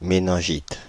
Ääntäminen
Ääntäminen Paris: IPA: [me.nɛ̃.ʒit] France (Île-de-France): IPA: /me.nɛ̃.ʒit/ Haettu sana löytyi näillä lähdekielillä: ranska Käännös Substantiivit 1. менингит Suku: f .